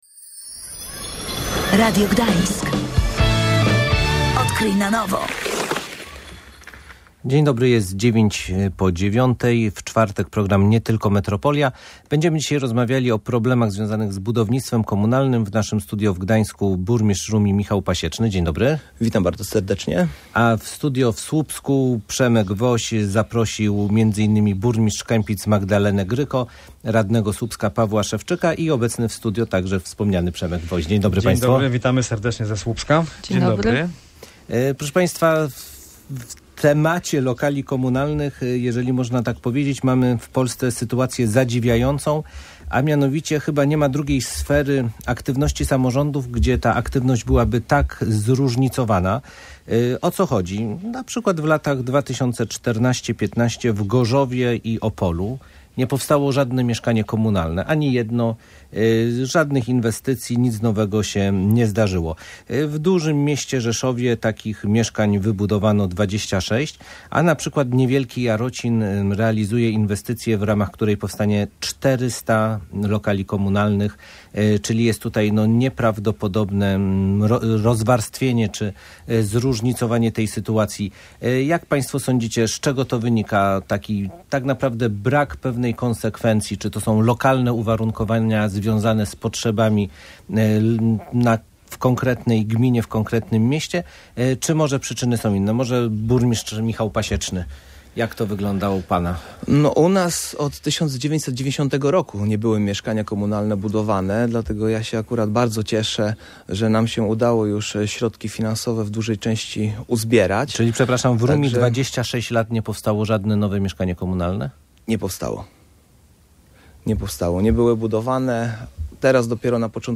Gośćmi Radia Gdańsk byli Michał Pasieczny, burmistrz Rumi, Paweł Szewczyk, radny ze Słupska i Magdalenę Gryko, burmistrz Kępic.